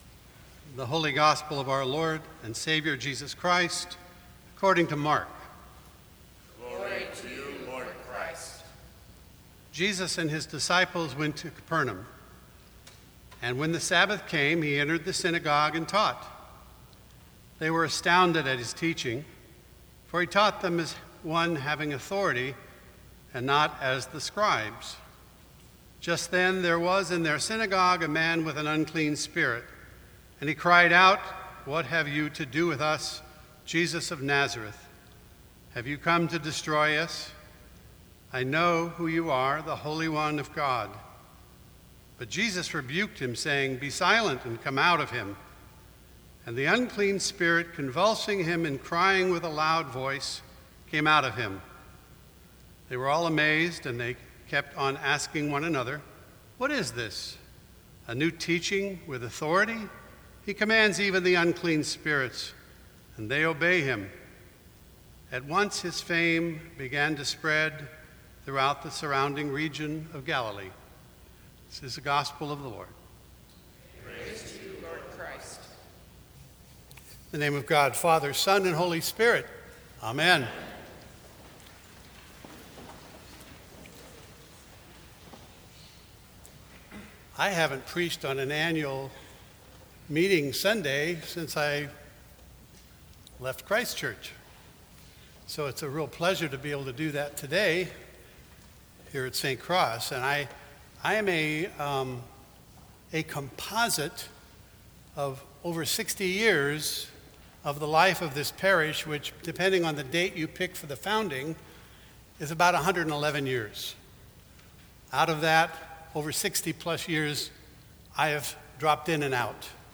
Sermons from St. Cross Episcopal Church The DNA of Jesus Jan 29 2018 | 00:12:41 1x 00:00 / 00:12:41 Subscribe Share Apple Podcasts Spotify Overcast RSS Feed Share Link Embed